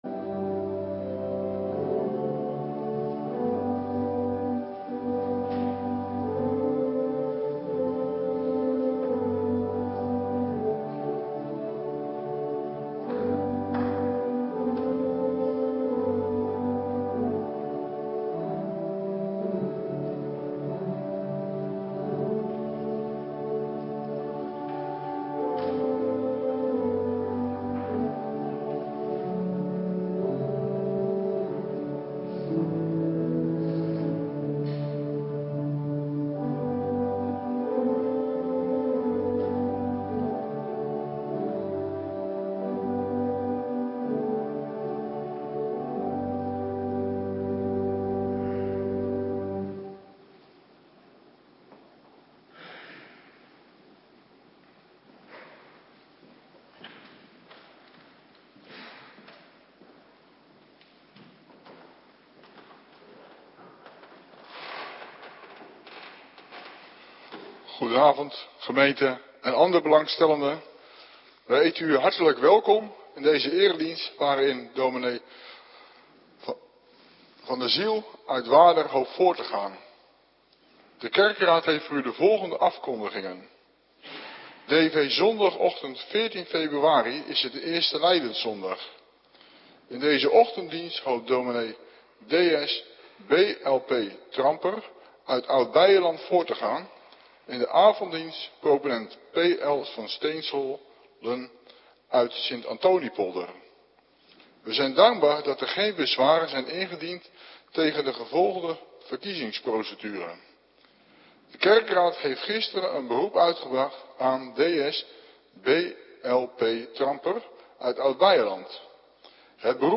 Avonddienst - Cluster 2
Locatie: Hervormde Gemeente Waarder